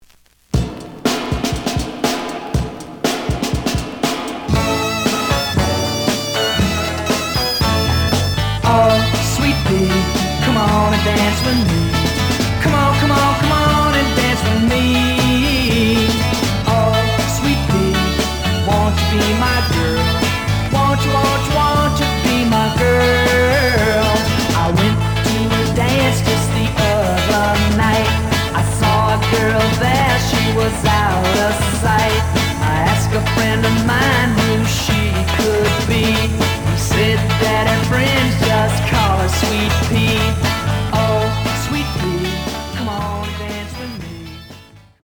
The audio sample is recorded from the actual item.
●Genre: Rock / Pop
Some click noise on first half of A side due to scratches.